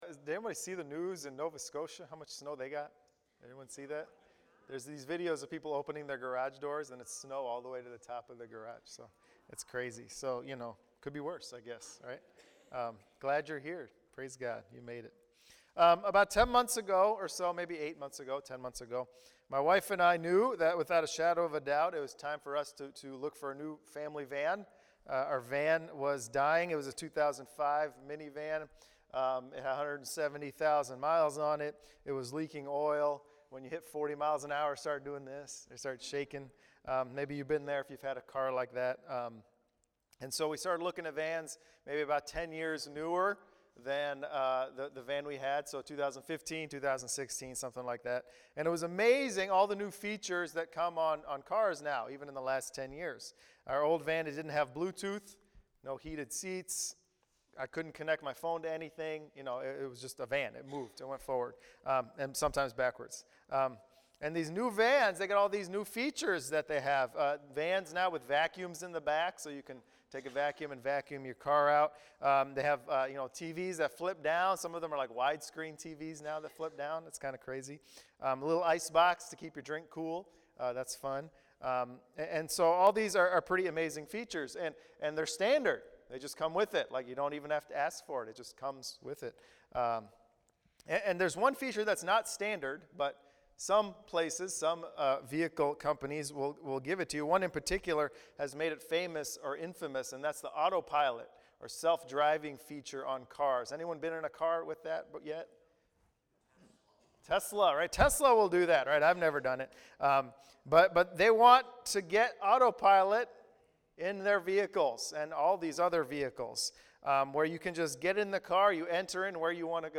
Sermon on casting vision for 2020 at Messiah Church.